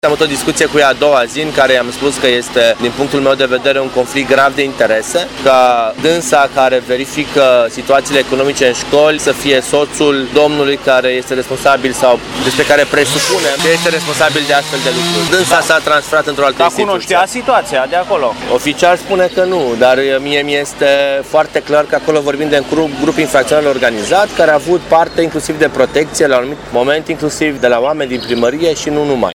Viceprimarul Ruben Lațcău susține că, în urma acestei situații, ea a fost obligată să plece din primărie.